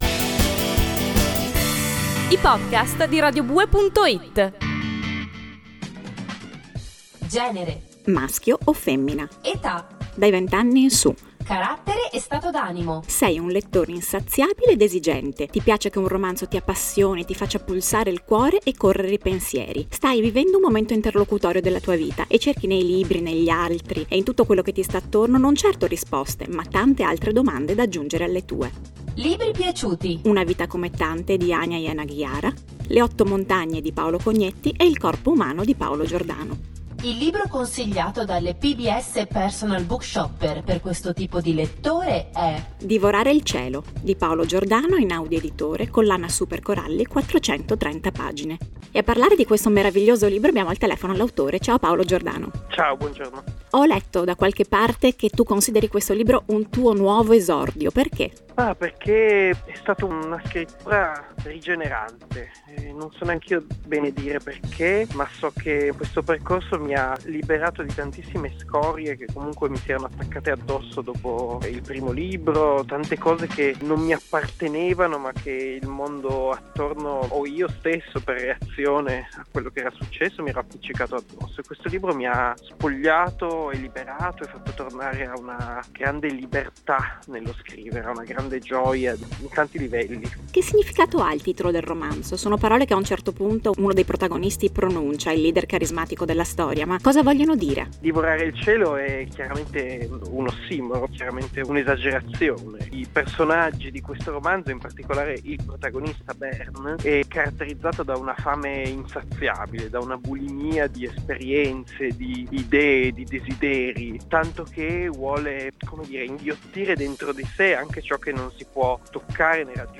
Divorare il cielo, intervista a Paolo Giordano